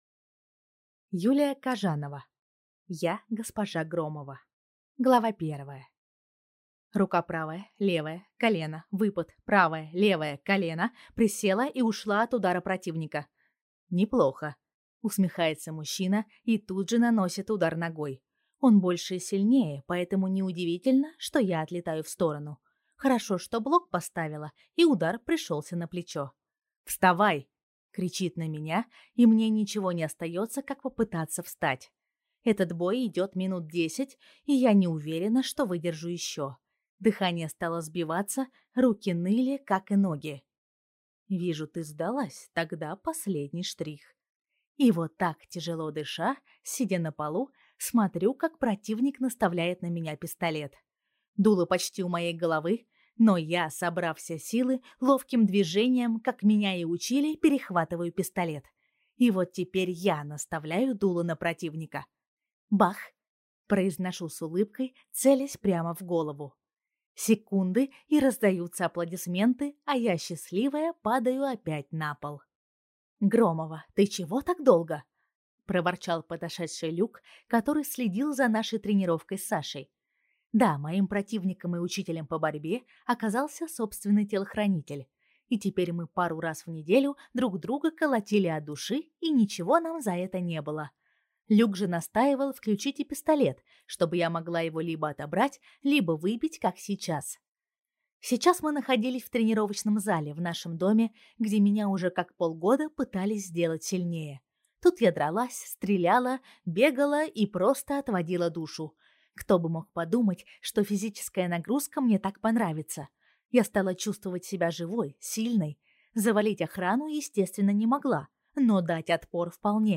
Аудиокнига Я Госпожа Громова | Библиотека аудиокниг
Прослушать и бесплатно скачать фрагмент аудиокниги